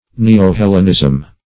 Neo-Hellenism \Ne`o-Hel"len*ism\, n.